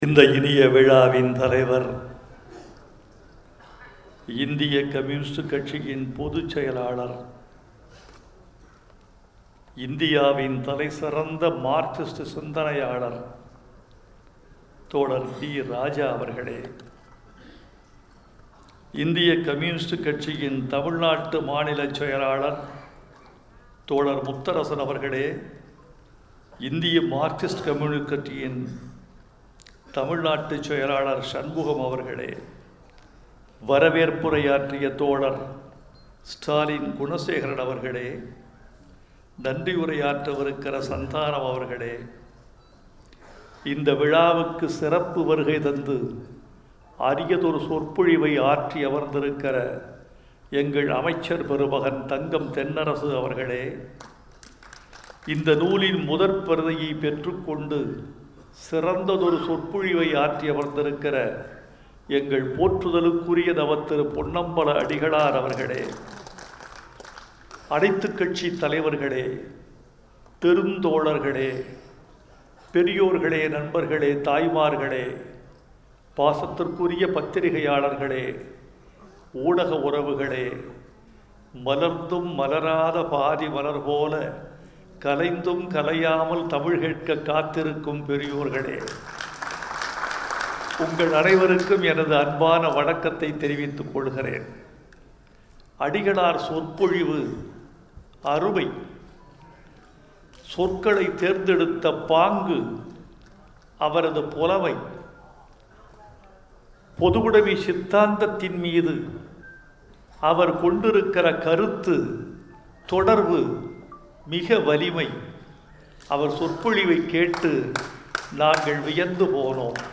‘‘இந்த இரண்டு தொகுப்புகளில் நிறைந்திருப்பது என்ன தெரியுமா? தியாகம் தியாகம் மட்டும்தான். தியாகம் இல்லாத இலக்கியங்கள் வெற்றிபெறுவதில்லை. தியாகம் இல்லாத போர்க்களம் நிறைவு பெறுவதில்லை’’ என்று கவிப்பேரரசு வைரமுத்து பலத்த கரவொல்லிக்கு இடையே கூறினார். இந்த நூல் வெளியீட்டு விழாவில் அவர் ஆற்றிய உரை வருமாறு :